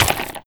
SPLAT_Generic_24_mono.wav